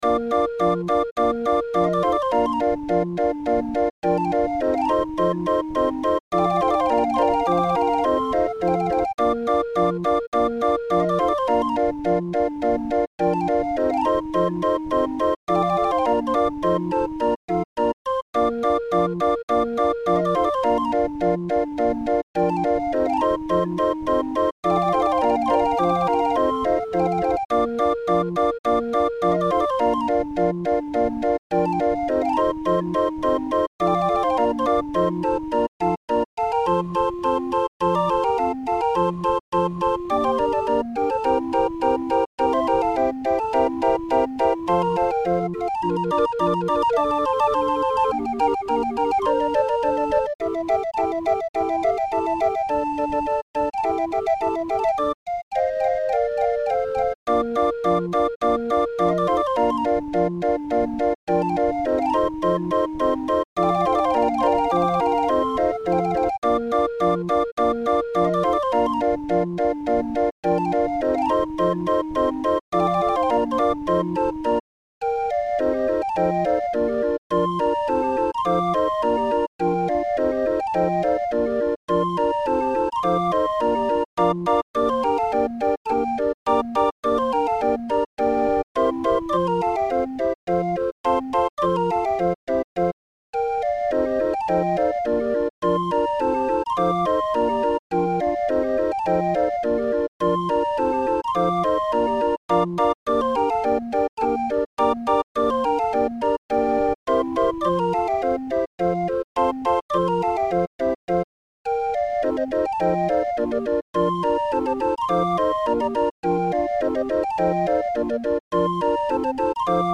Musikrolle für 20-er Raffin